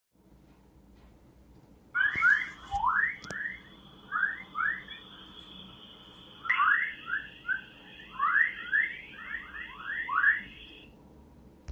Essas ondas, conhecidas por emitirem sons semelhantes ao canto de pássaros quando convertidas em áudio, ocorrem em regiões de plasma — o quarto estado da matéria.
Durante esse processo, as alterações no fluxo de elétrons intensificam as ondas, criando efeitos que podem ser convertidos em sons semelhantes a assobios.
Audio-reconstruction-of-the-chirping-chorus-_1_.mp3